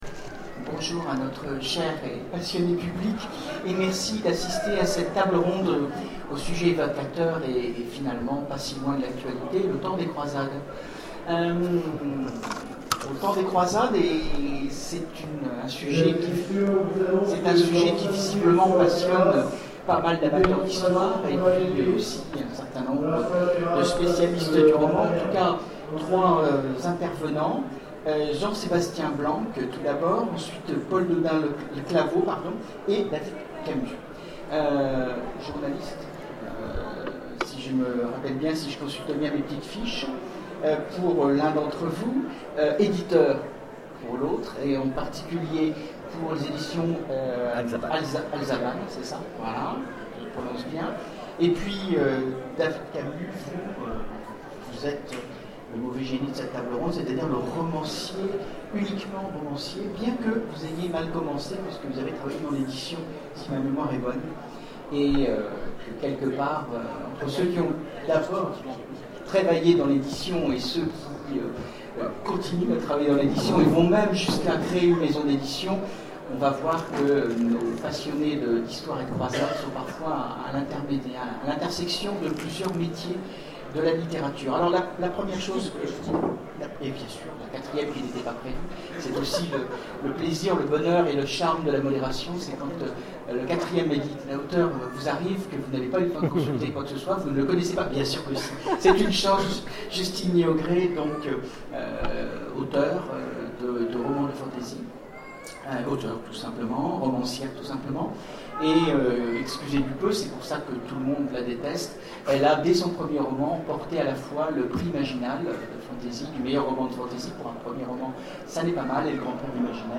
Zone Franche 2014 : Conférence Au temps des croisades
Conférence